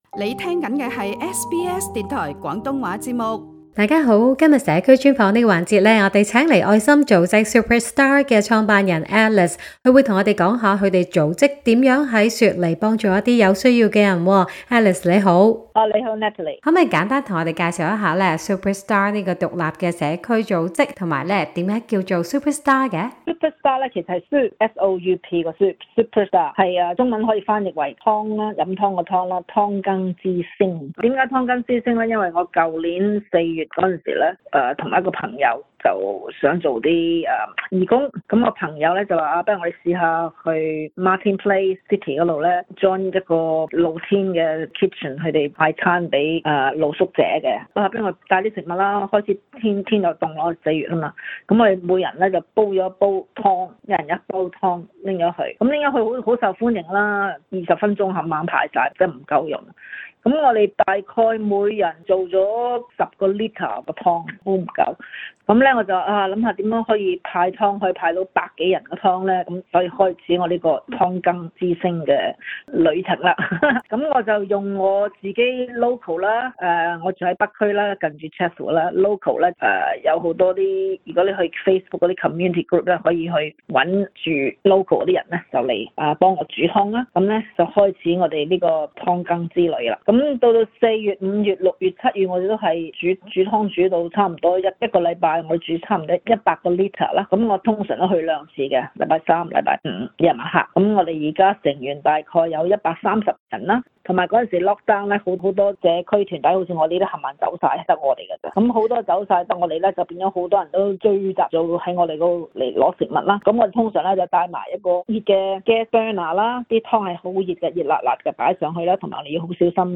【社區專訪】雪梨義工組織SouperStar : 愛心湯送暖行動